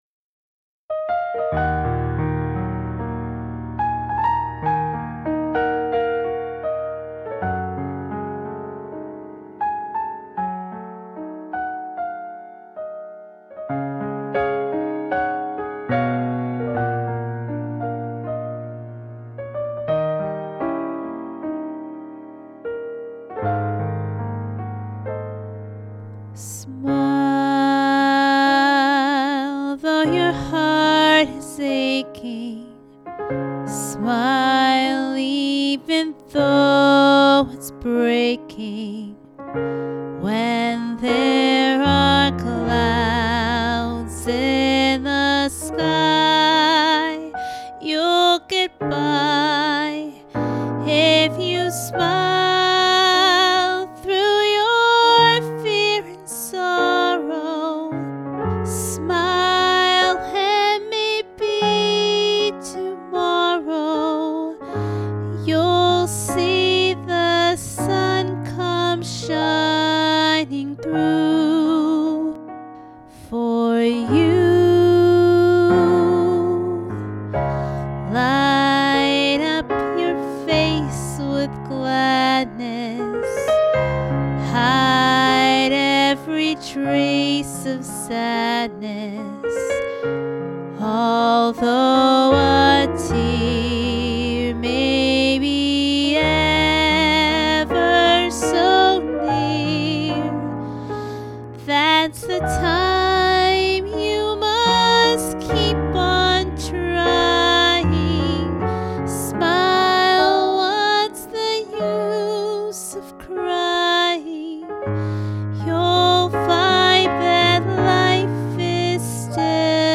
Captured at Harold Parker State Park in Andover, MA